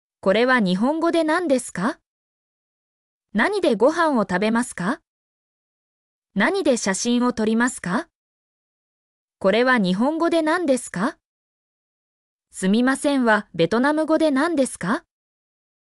mp3-output-ttsfreedotcom_n1NBtQoH.mp3